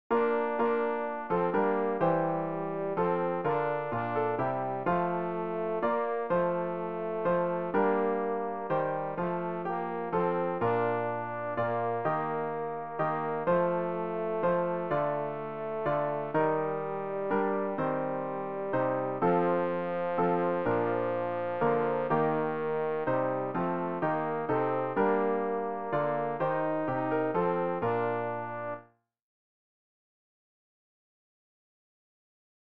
rg-480-o-herrlicher-tag-o-froehliche-zeit-tenor.mp3